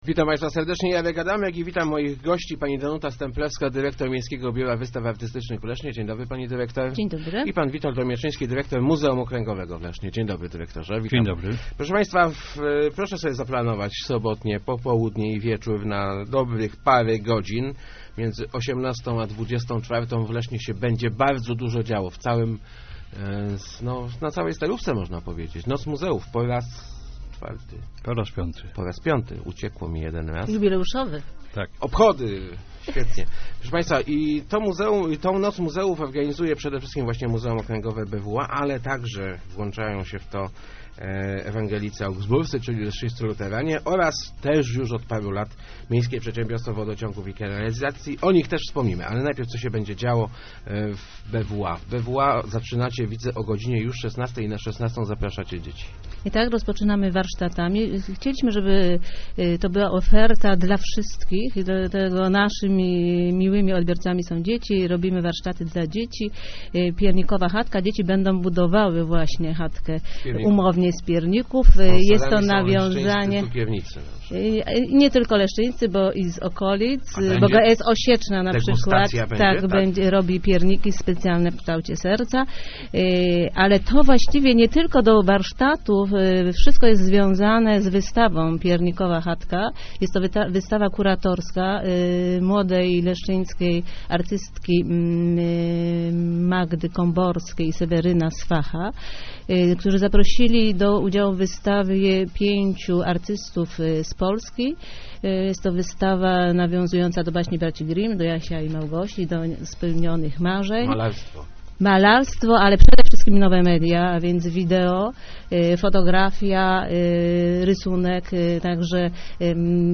mówili w Rozmowach Elki